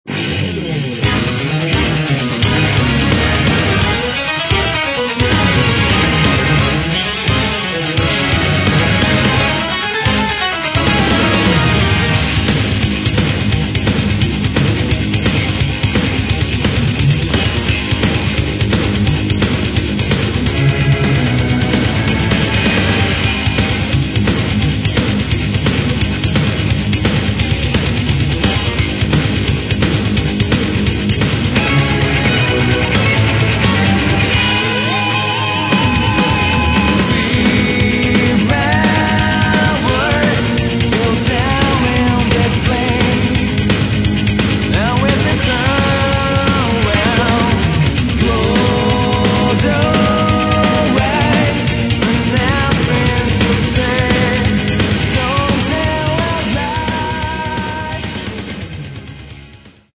Metal
Неоклассический шедевр!